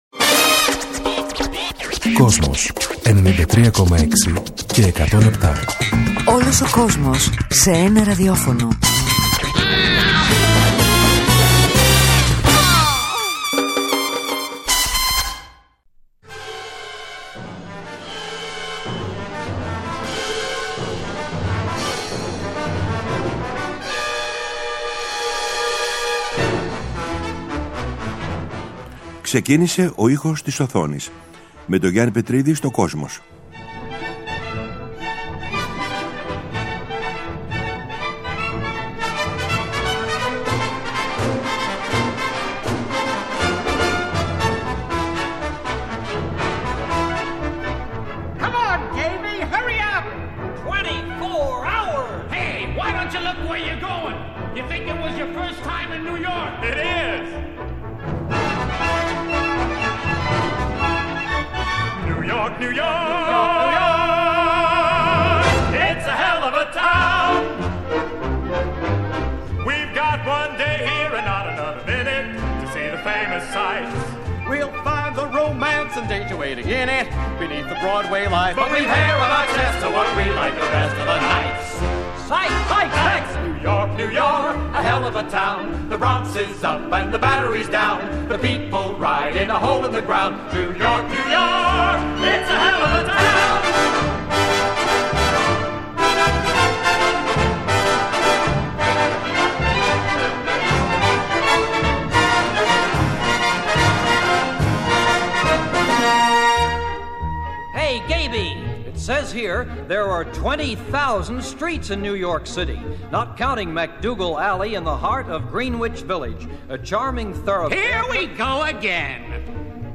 μουσική από το musical